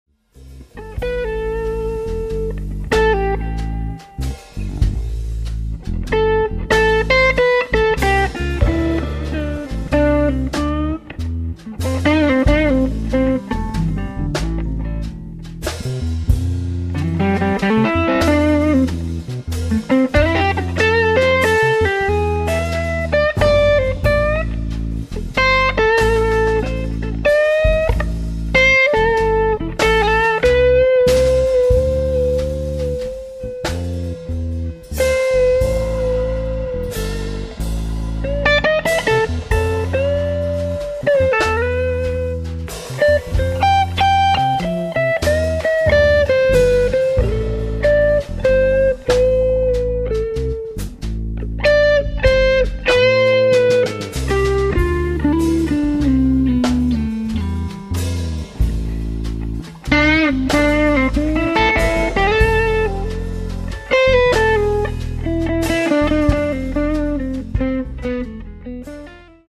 batteria
chitarra
un bel blues minore strumentale